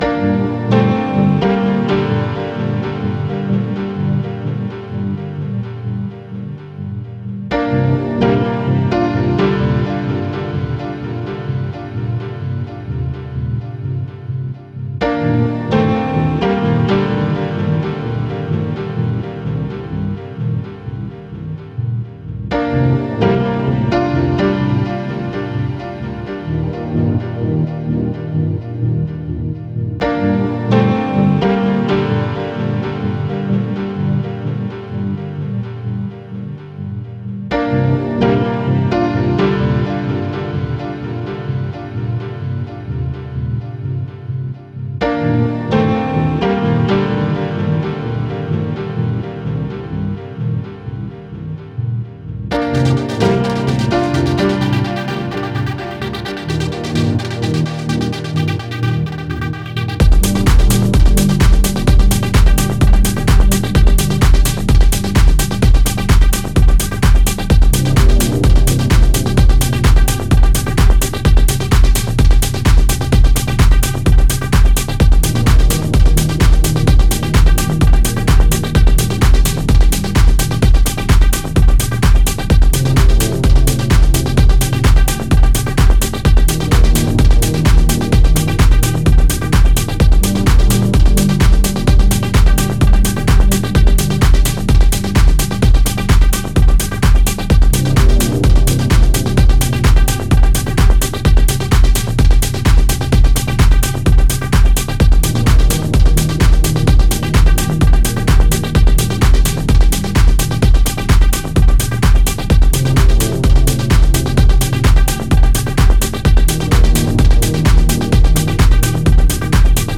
Genre: psytrance.